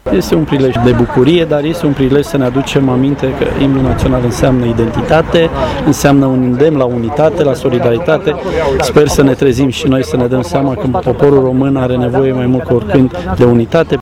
Instituția Prefectului Județului Brașov în parteneriat cu Garnizoana Braşov a organizat, în Piaţa Tricolorului, o manifestare specială, dedicată sărbătoririi Zilei Imnului Naţional al României.
La festivitate a luat parte şi ministrul Tineretului şi Sportului, Marius Dunca